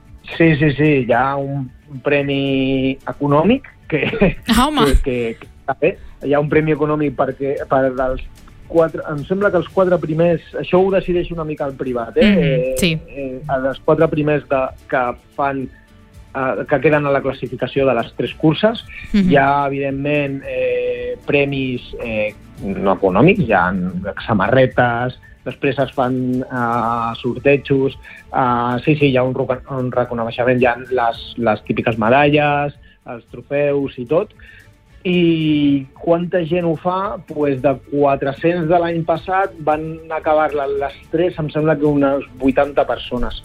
Supermatí - entrevistes
I per parlar-ne ens ha visitat al Supermatí el regidor d’esports de l’Ajuntament de Castell d’Aro, Platja d’Aro i s’Agaró, Marc Medina.